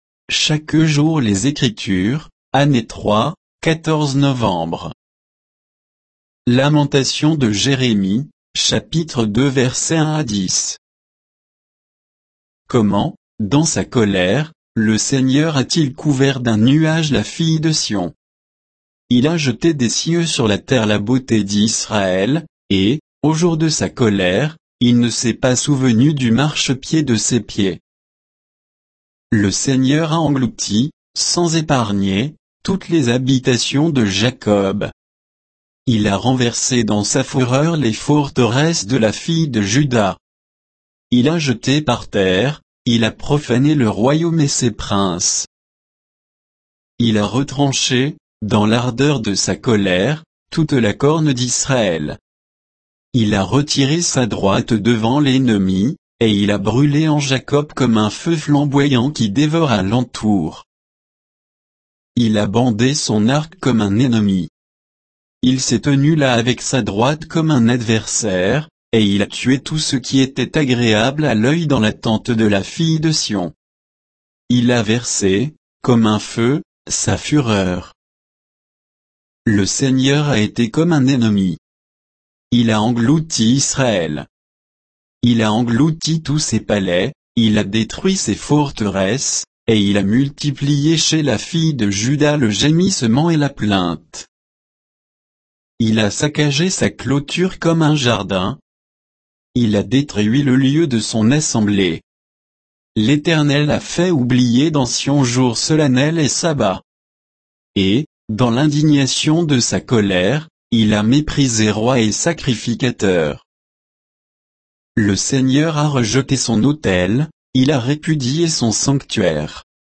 Méditation quoditienne de Chaque jour les Écritures sur Lamentations de Jérémie 2, 1 à 10